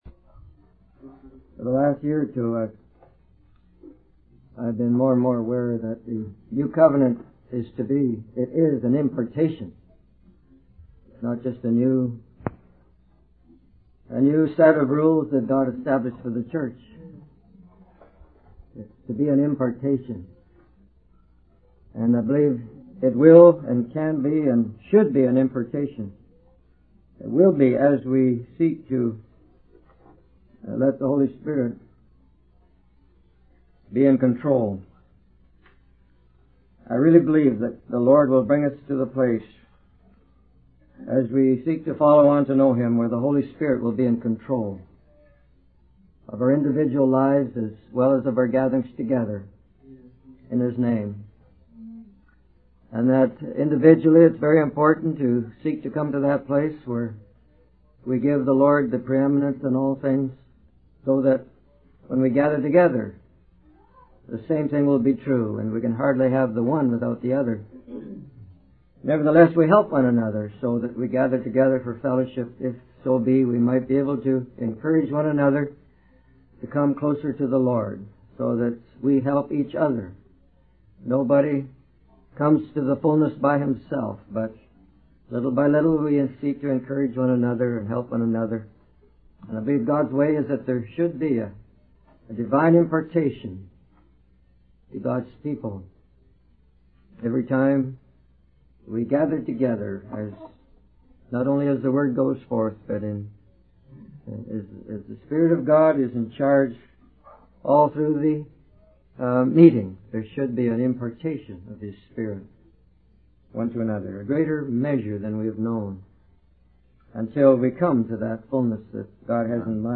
In this sermon, the speaker emphasizes the importance of perseverance and patience in the Christian journey. He highlights how society's obsession with instant gratification can hinder our spiritual growth.